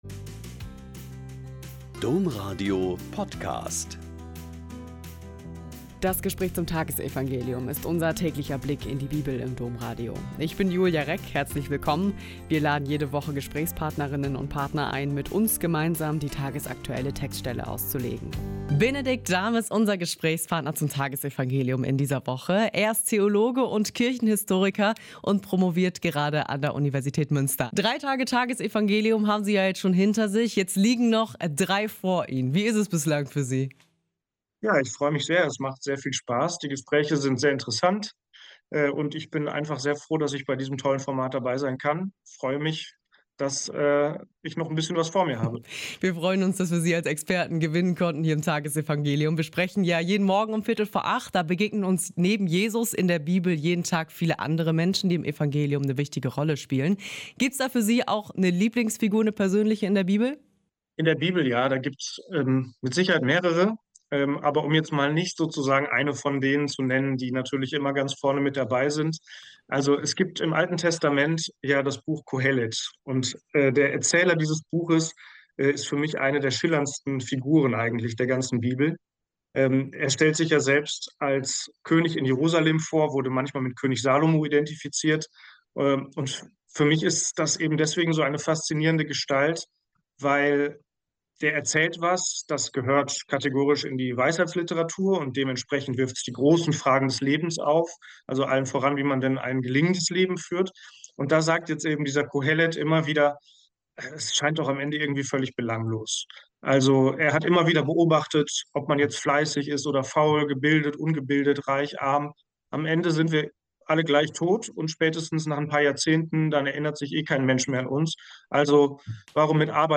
Mt 13,47-52 - Gespräch